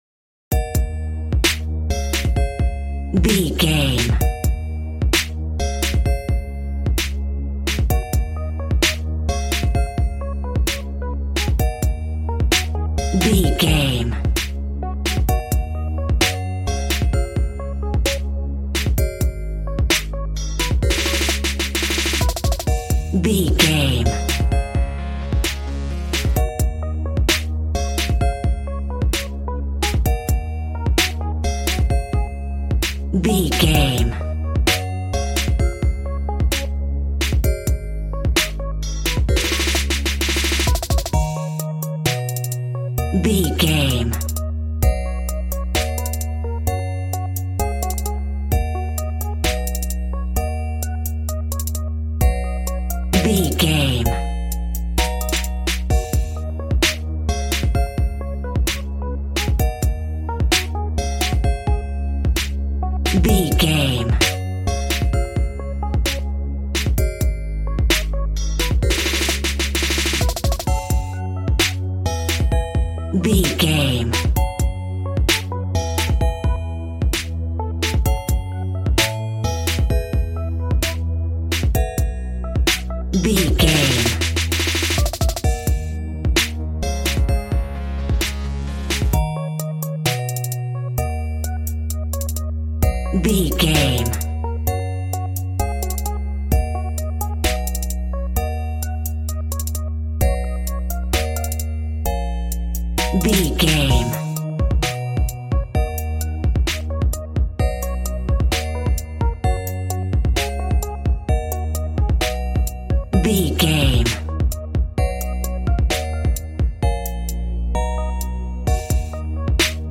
Aeolian/Minor
A♭
calm
smooth
synthesiser
piano